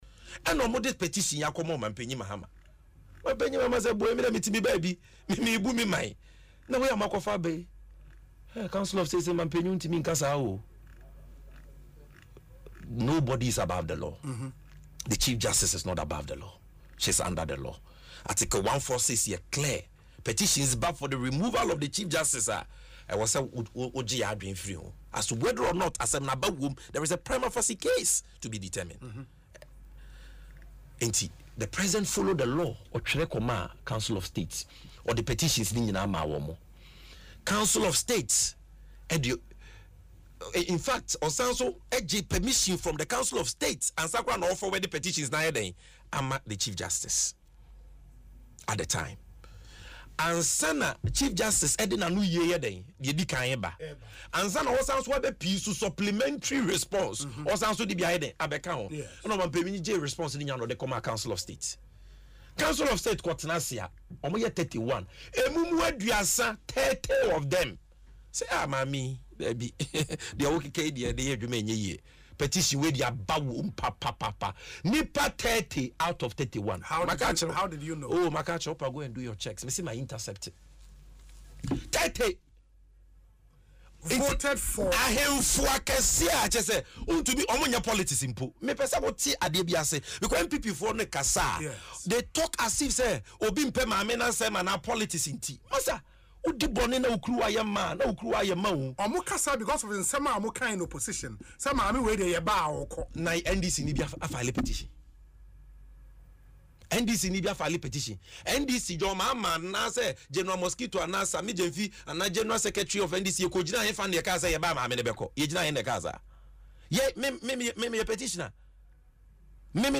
In an interview on Asempa FM’s Ekosii Sen, Gyamfi explained that although he could not name the individual members, their advice significantly influenced the President’s decision.